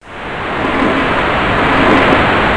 openshwr.mp3